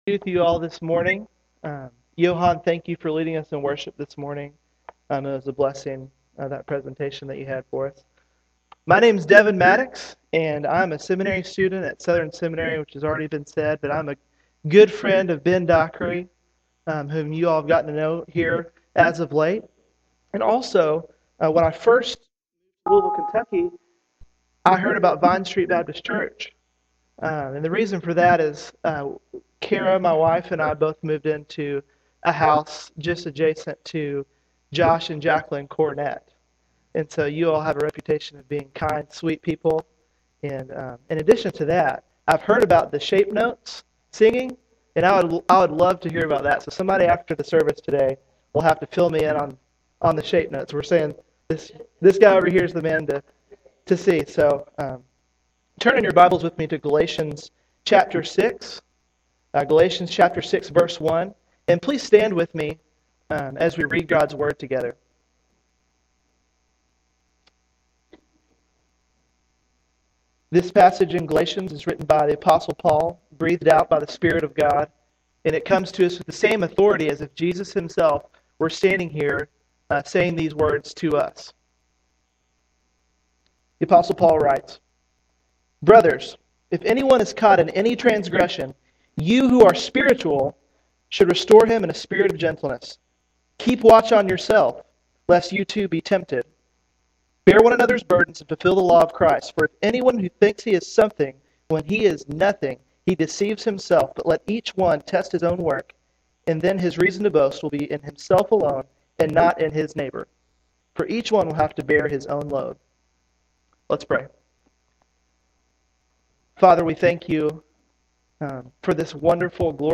Paul challenges us to look deeper than just following the law and to start measuring yourself against living for Christ. Click here to listen to the sermon audio.